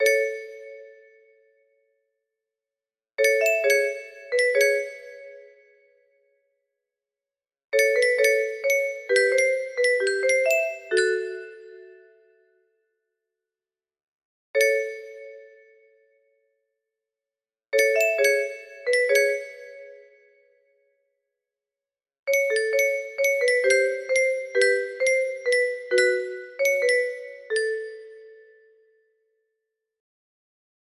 49-56 music box melody